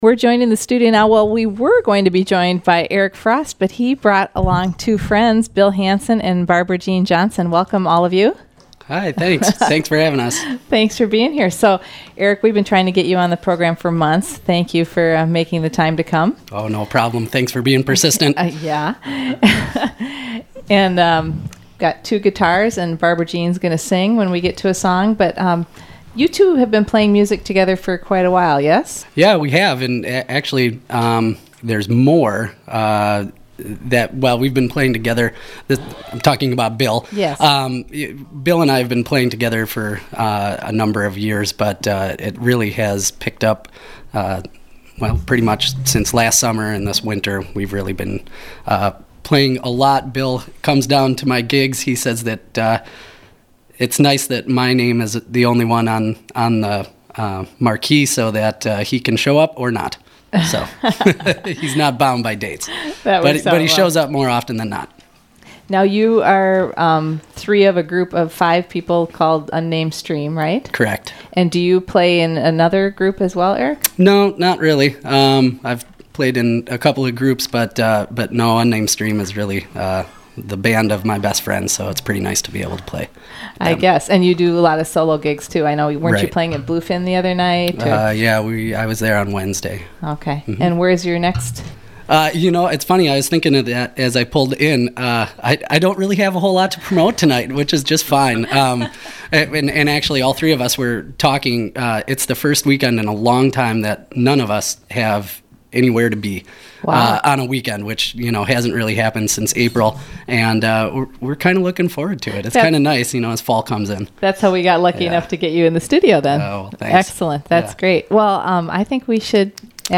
Singer/songwriter
Studio A